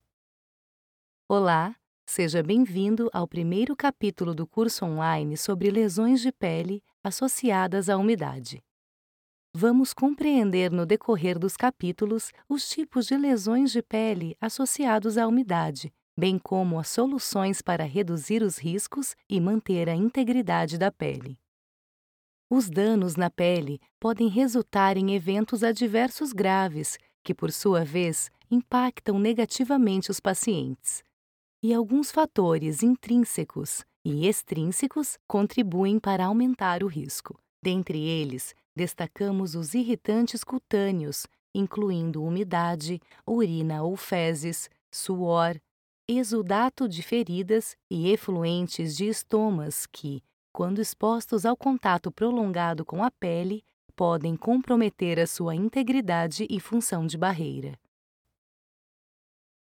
Sprechprobe: eLearning (Muttersprache):
My vocal personality ranges from a youth, which inspires lightness, energy and friendliness, to a “maturity”, which conveys confidence, calm and drama. Own studio in the countryside of São Paulo, Brazil. With an excellent acoustic treatment system that offers excellent quality.